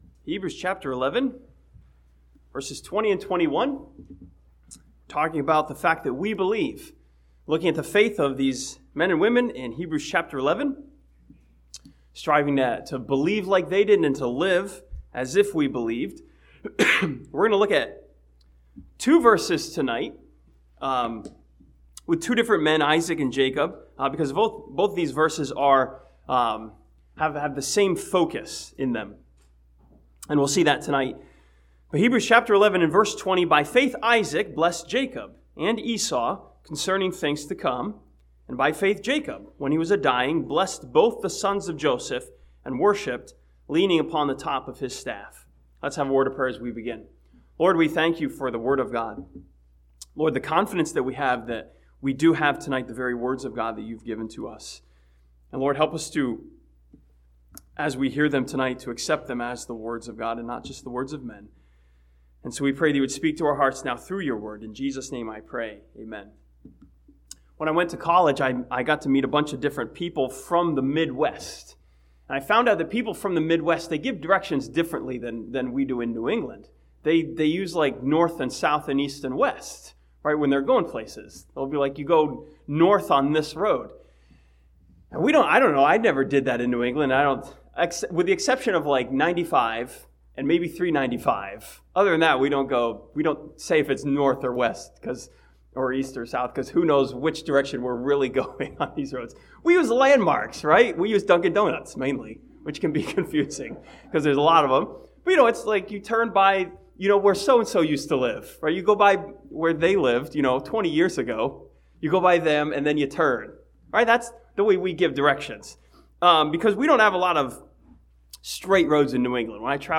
This sermon from Hebrews chapter 11 challenges us to have the faith of Isaac and Jacob and to walk by faith.